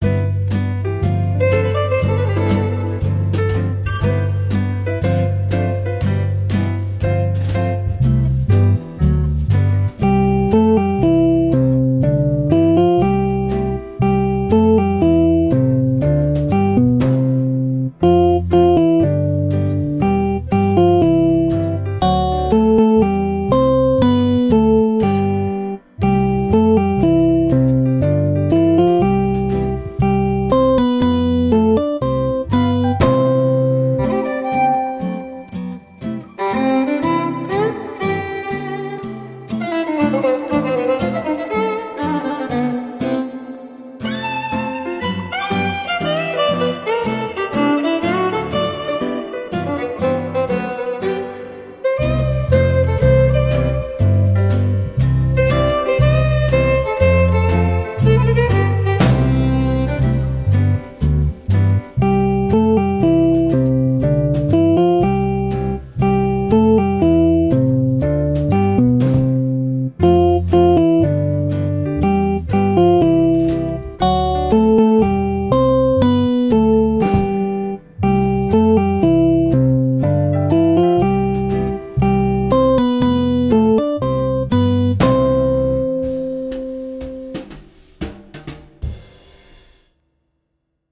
Deux versions instrumentales